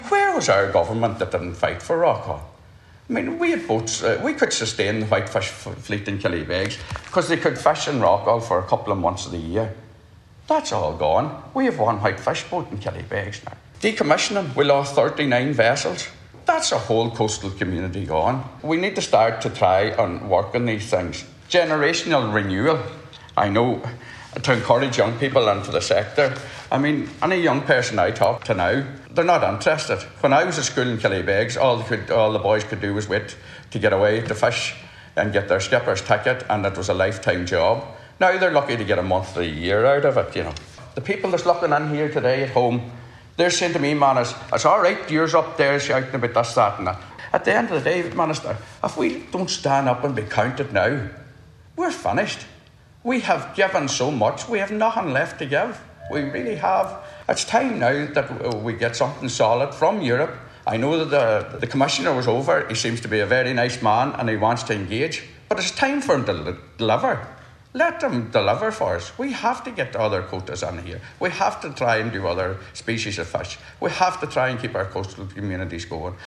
Senator Manus Boyle was speaking during a discussion on the floor of the Seanad about the state of the sea fisheries sector.